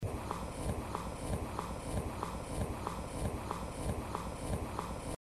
长笔触2.wav